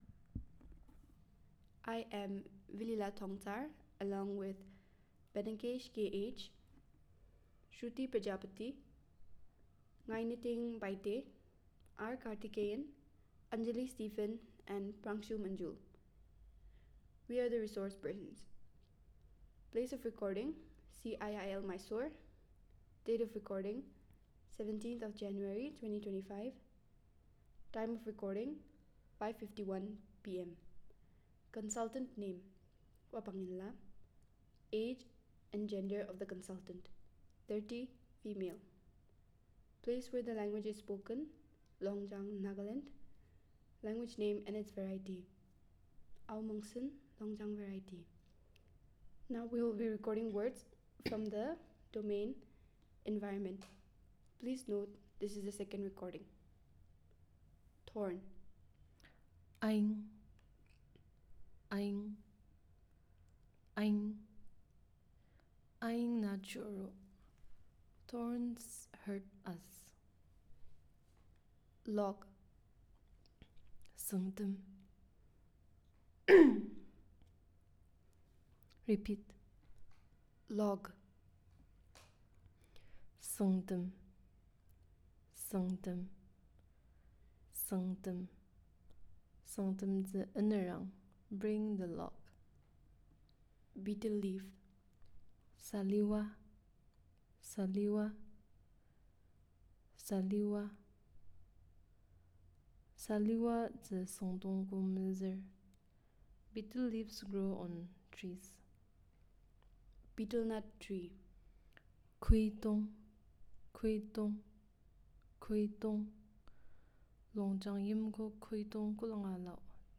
Elicitation of sentences on the domain of Environment